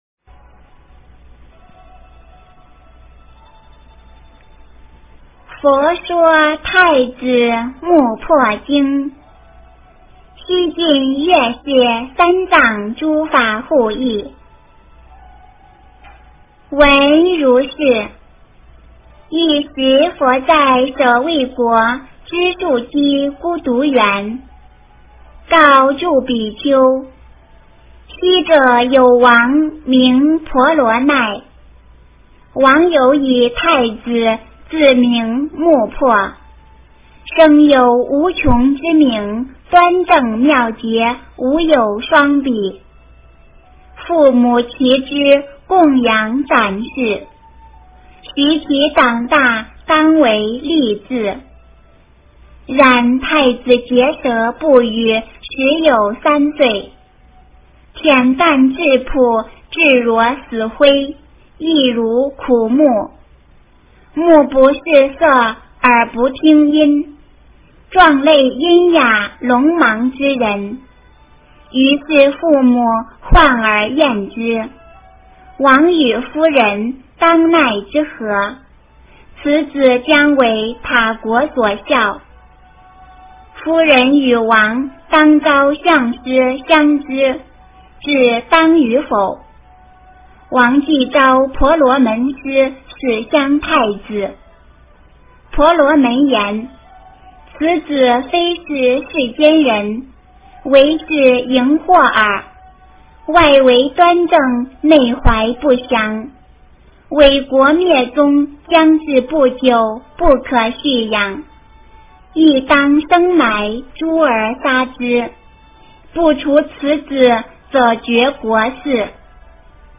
佛说太子墓魄经 - 诵经 - 云佛论坛